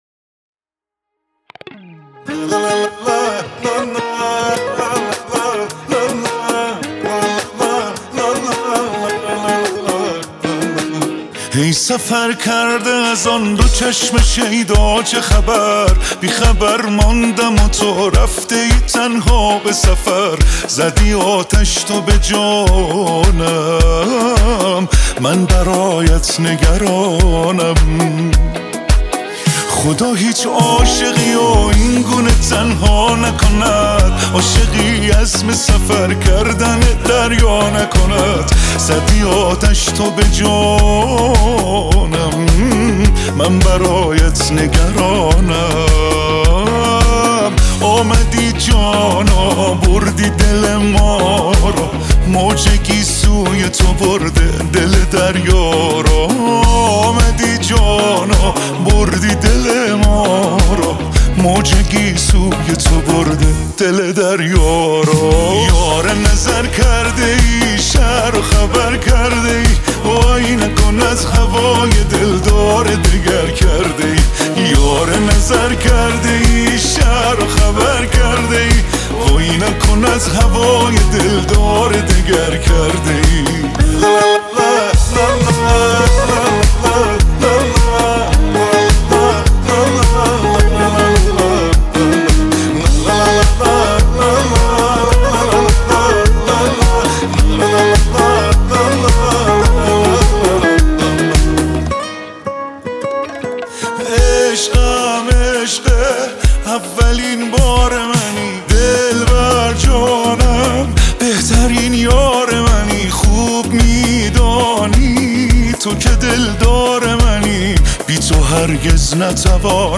یه آهنگ شاد و ریتمیک